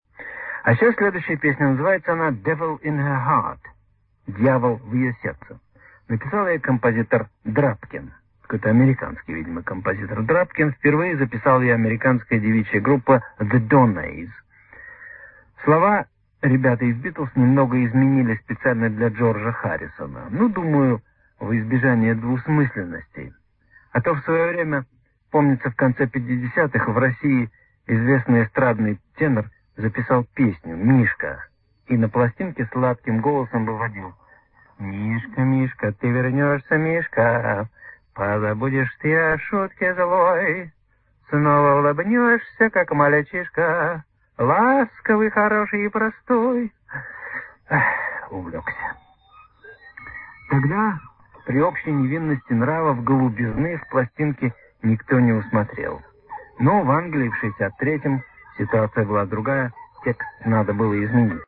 Фрагмент из радиопередачи Би-Би-Си «Рок-посевы» (14.01.1994).
«Мишка» звучит в исполнении (а капелла) Севы Новгородцева